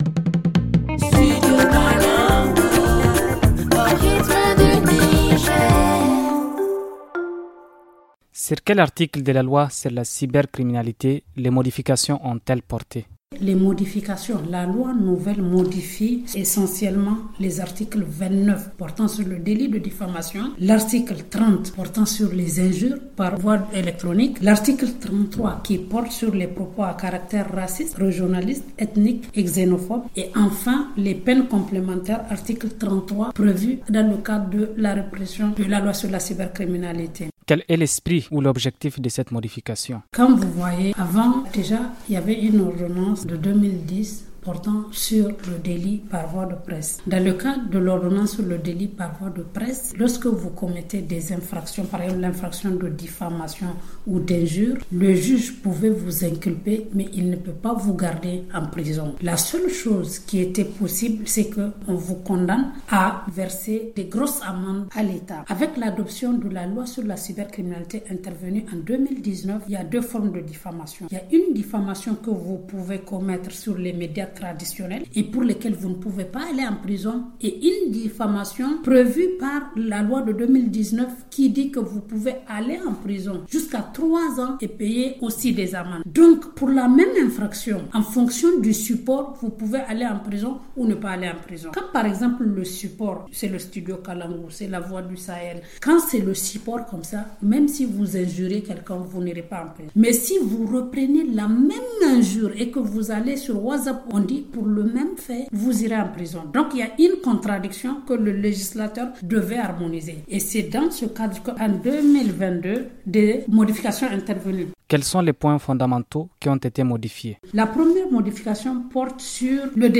Elle est interrogée